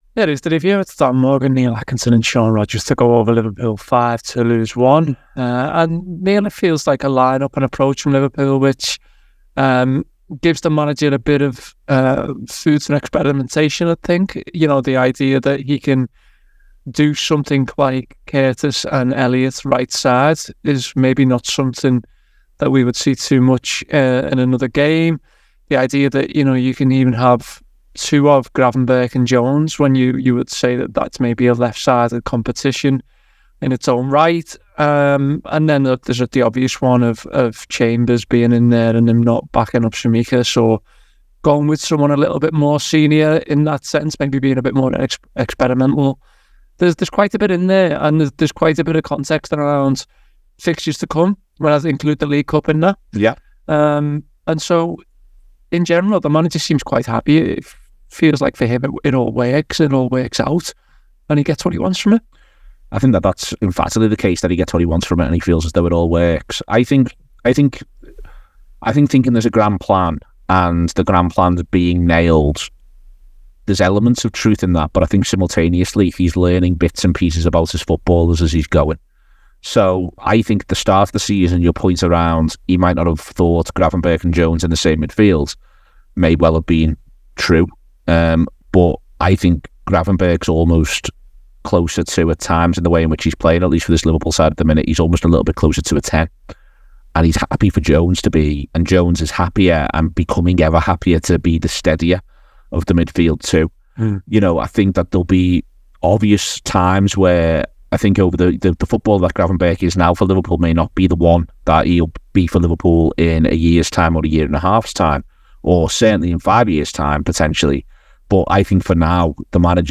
Below is a clip from the show – subscribe for more review chat around Liverpool 5 Toulouse 1…